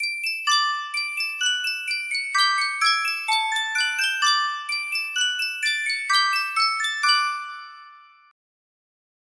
Sankyo Miniature Music Box - Mozart Turkish March AYG music box melody
Full range 60